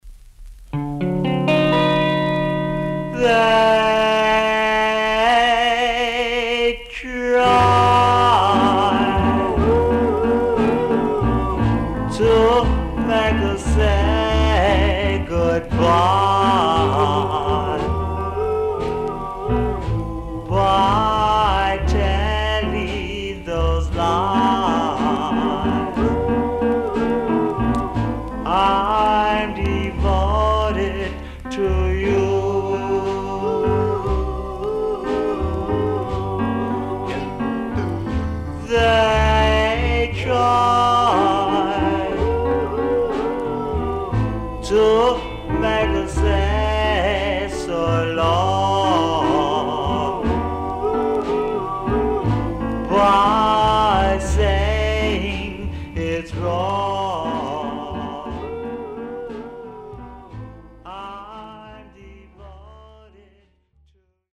盤面に光沢があり、音もしっかりしています。ほんの少々軽いパチノイズの箇所あり。